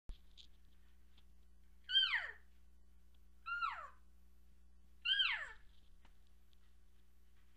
Calfchirp
Calf Elk Sounds Chirp The Elk calves make some of the same sounds as the cows. The difference is the higher pitch and shorter duration than the cow sounds.